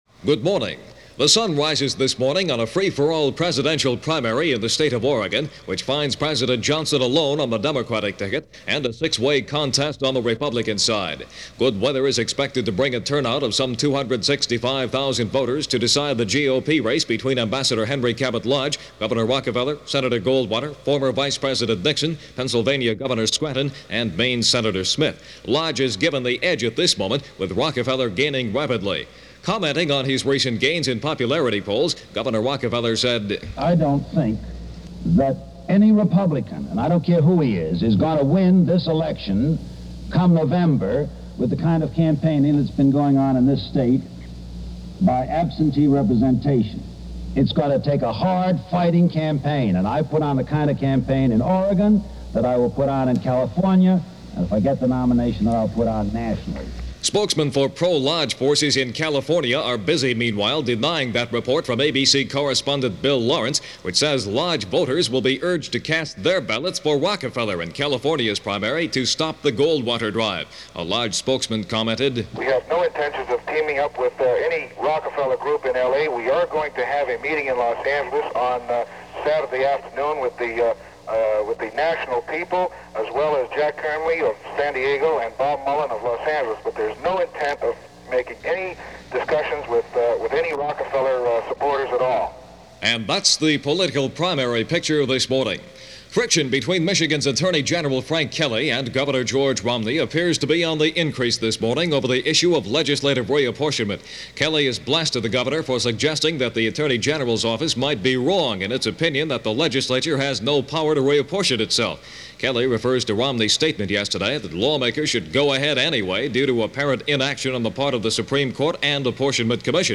– ABC Radio News via WXYZ-Detroit – May 15, 1964 –